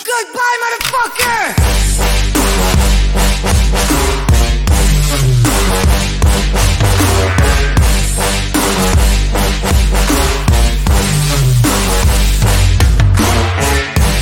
Goodbye with music